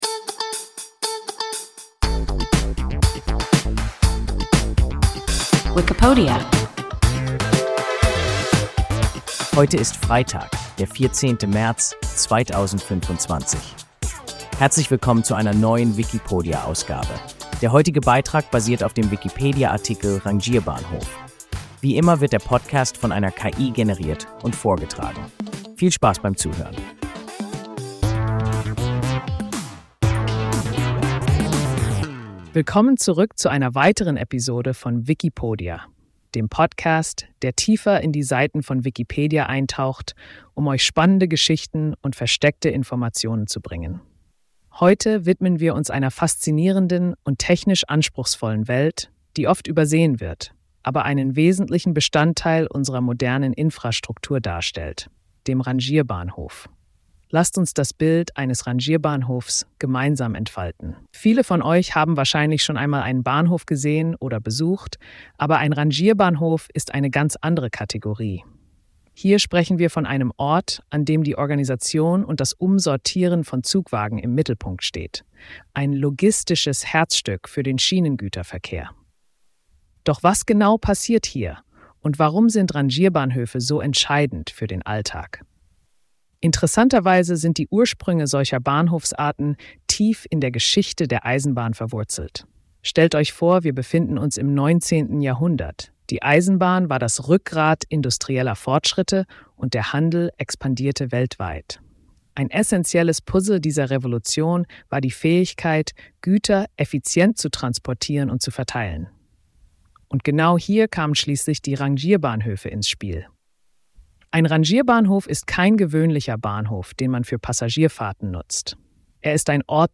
Rangierbahnhof – WIKIPODIA – ein KI Podcast